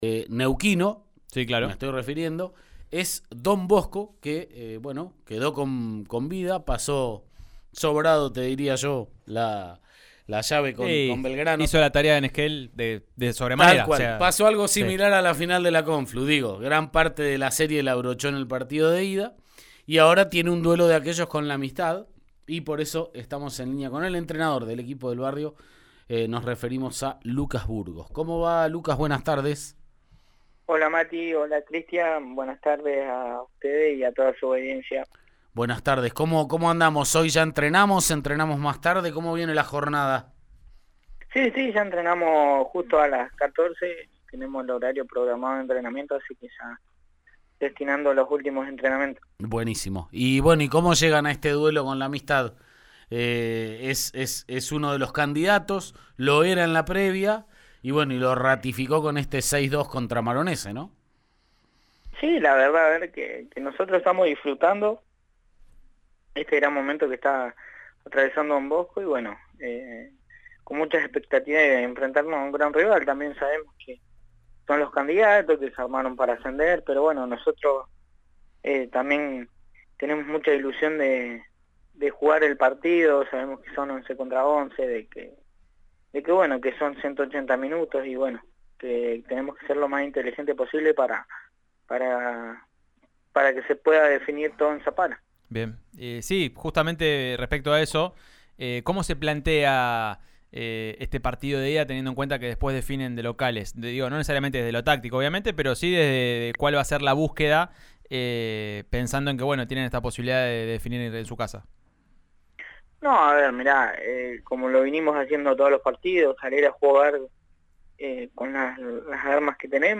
en diálogo con «Subite al Podio» de Río Negro Radio.